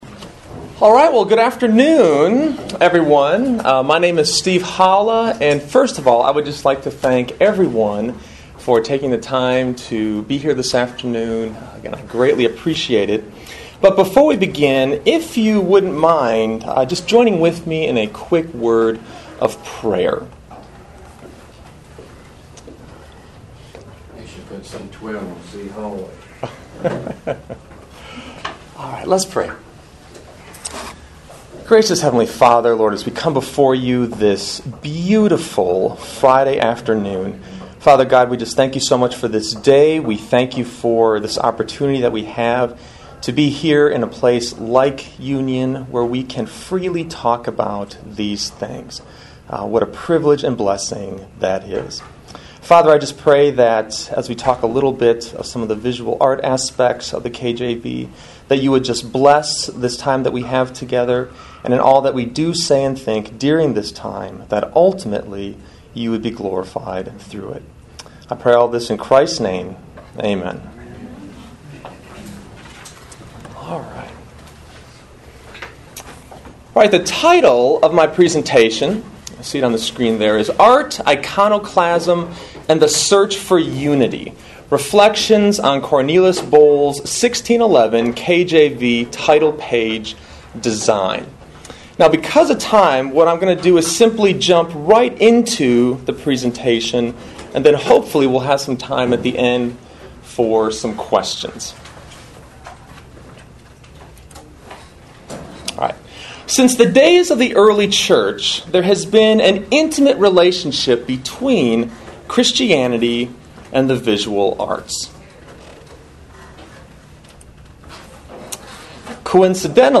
KJV400 Festival